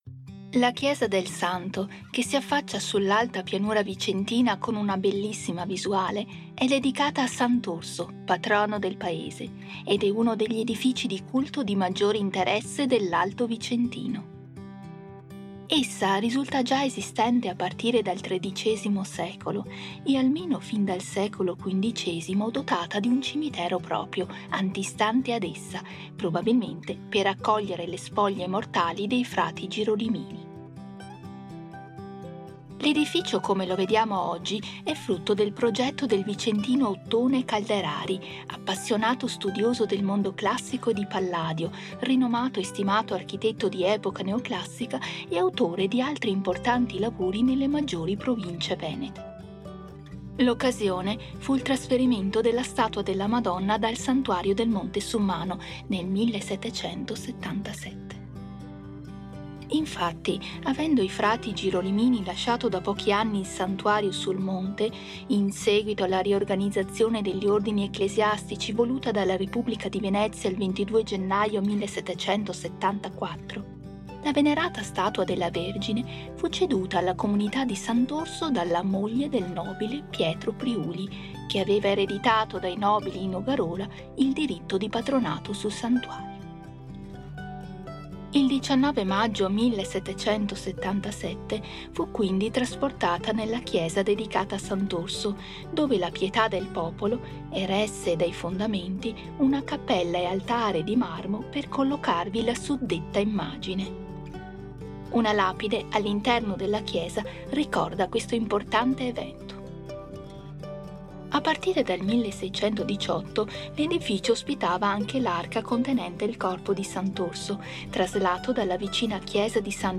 AUDIOGUIDA_Girolimini._02._La_chiesa_del_Santo.mp3